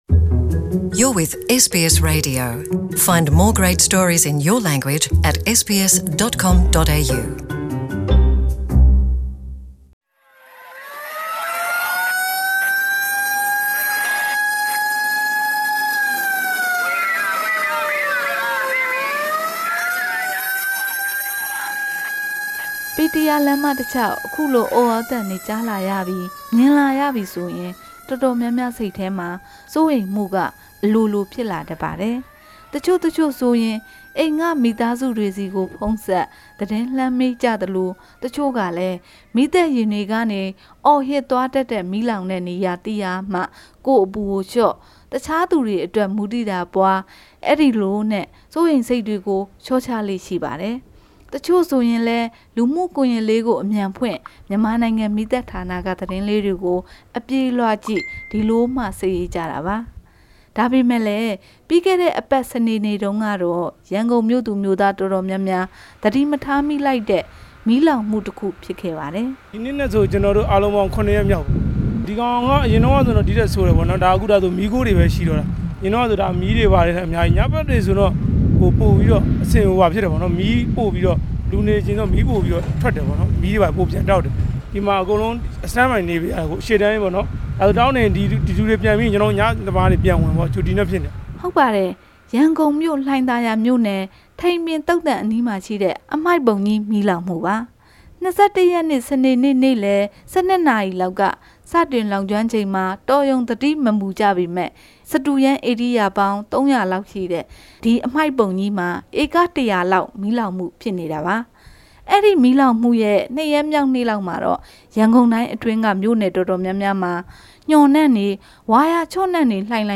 Report on garbage dump fire